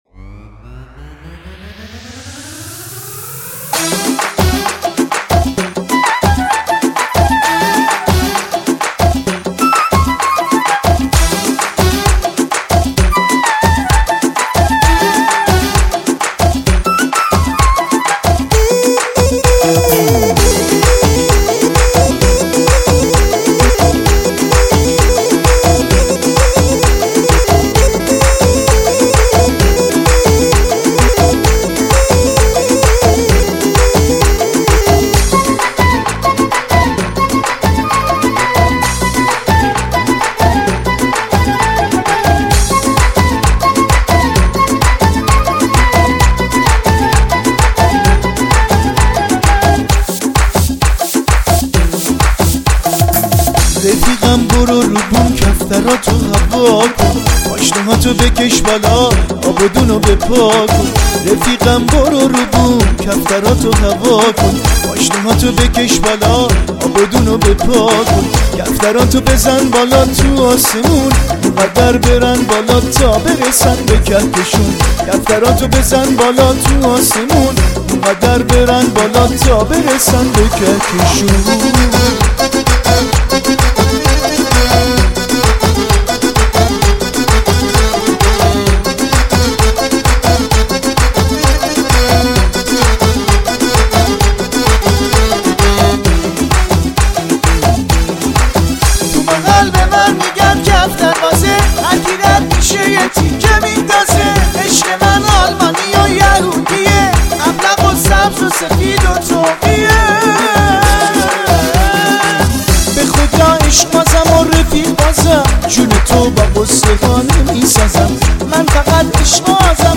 اهنگ شاد ایرانی